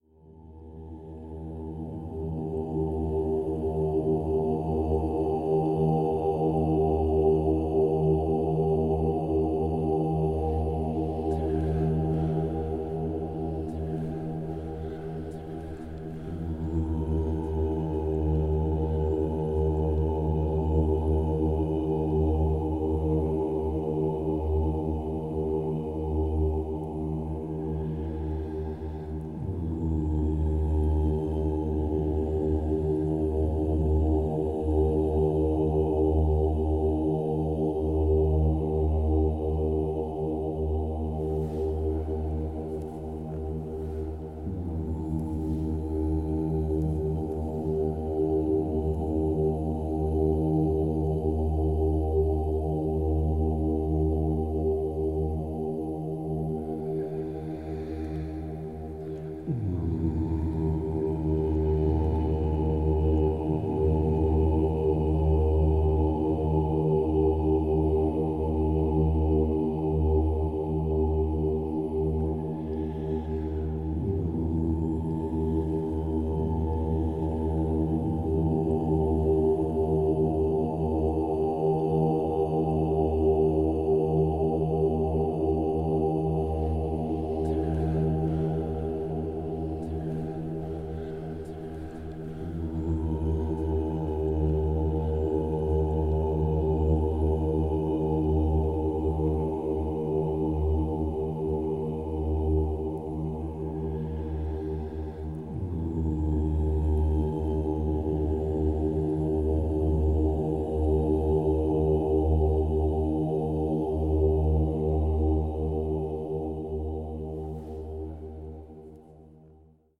Méditation du son jusqu’au silence de la dissolution